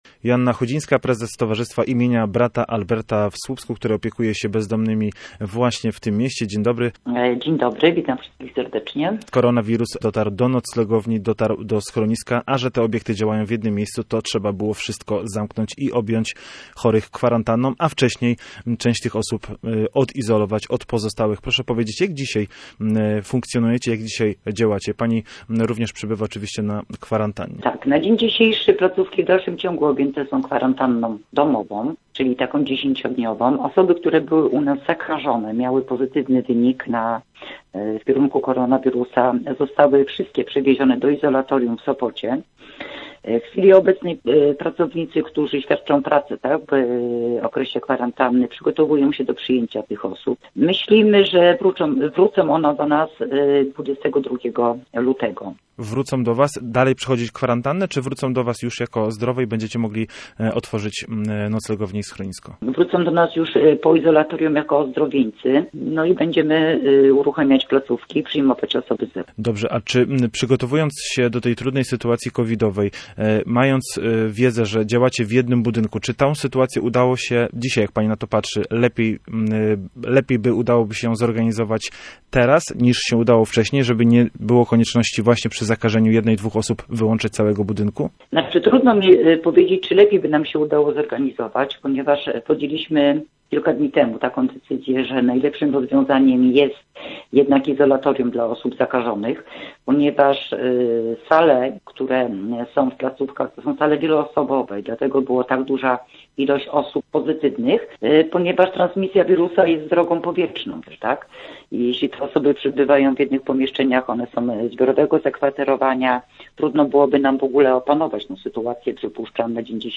Gość programu miejskiego w Słupsku tłumaczyła, jak z powodu pandemii zmieniono zasady przyjęć potrzebujących do schroniska i noclegowni. Zapraszamy do wysłuchania całej rozmowy: